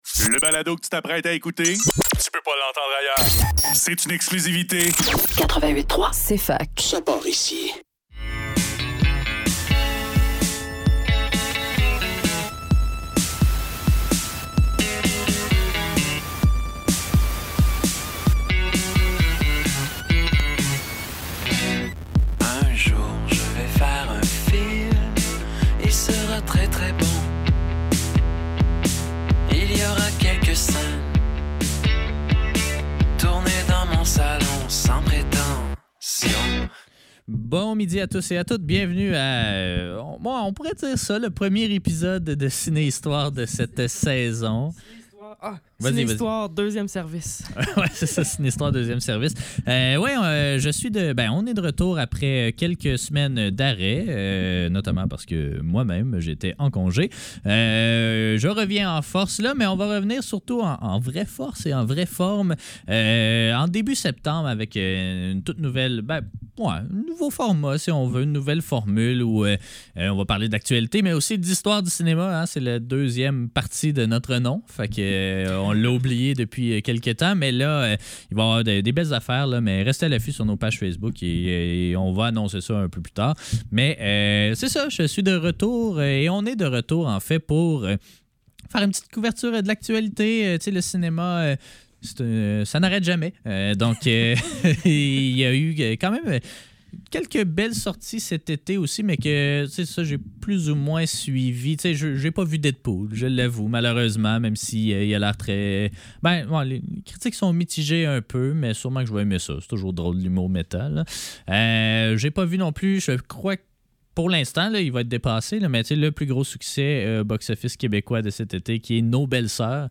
Entrevue avec Ricardo Trogi